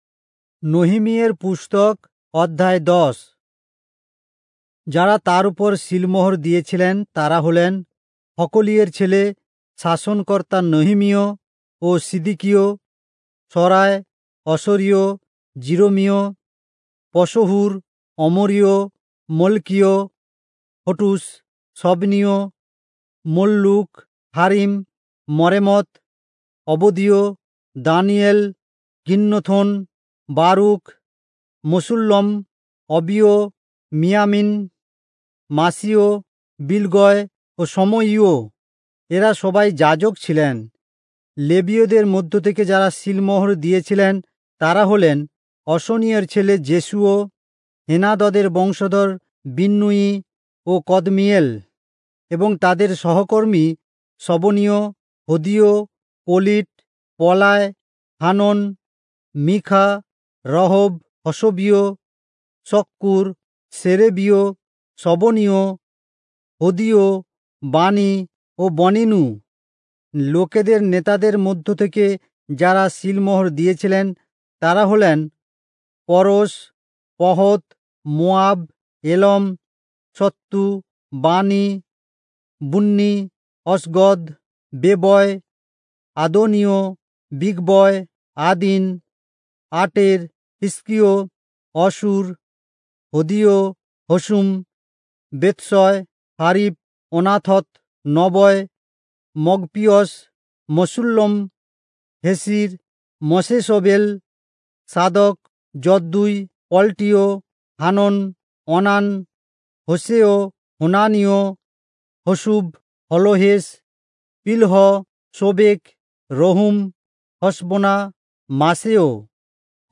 Bengali Audio Bible - Nehemiah 9 in Irvbn bible version